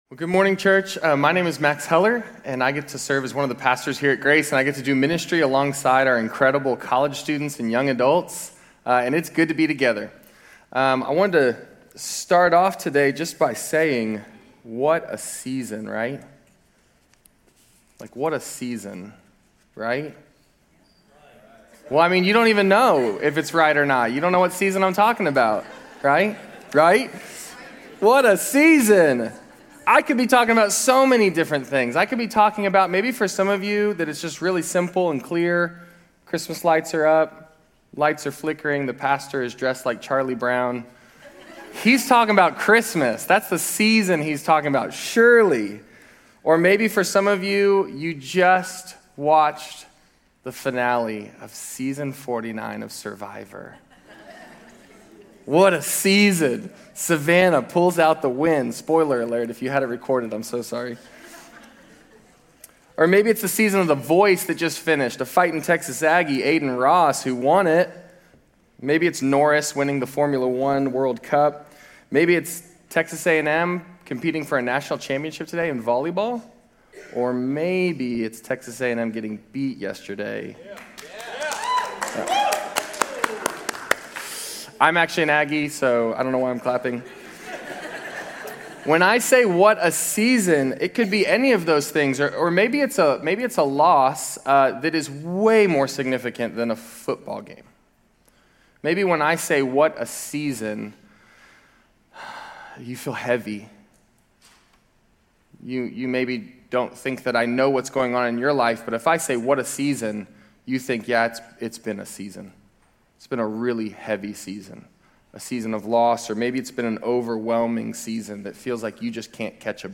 Grace Community Church University Blvd Campus Sermons 12_21 University Blvd Campus Dec 22 2025 | 00:39:01 1x 00:00 / 00:39:01 Subscribe Share RSS Feed Share Link Embed